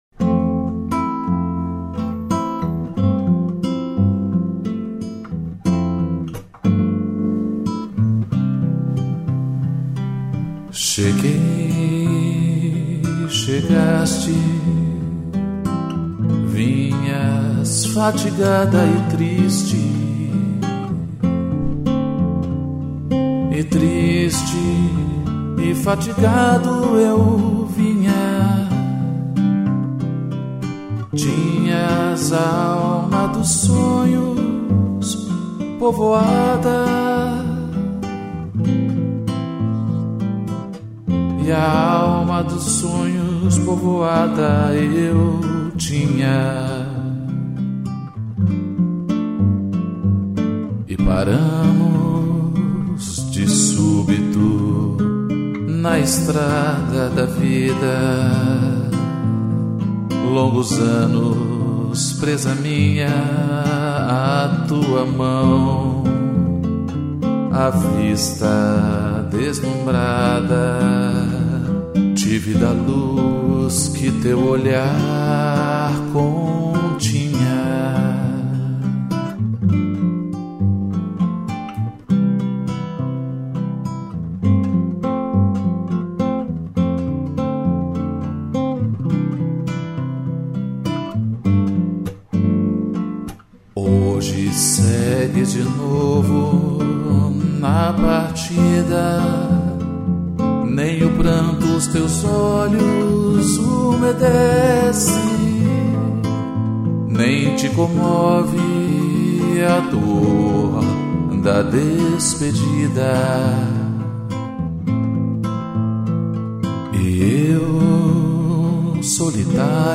interpretação violão e voz